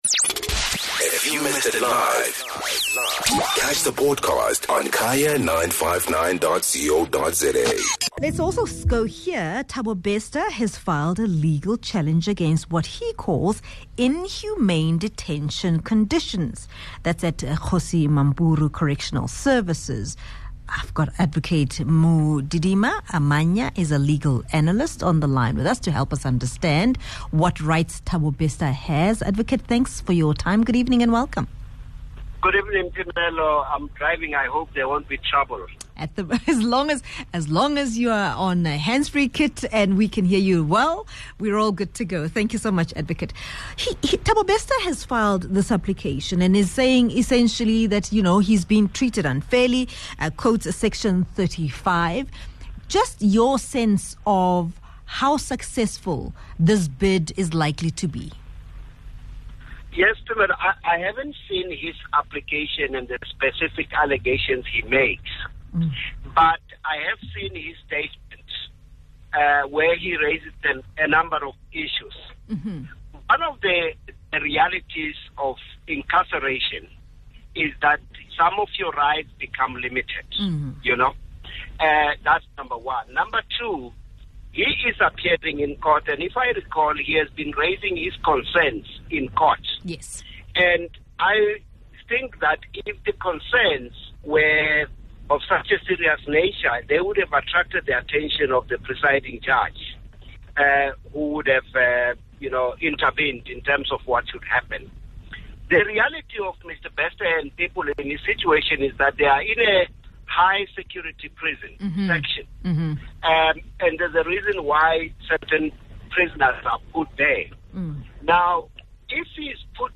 Legal Analyst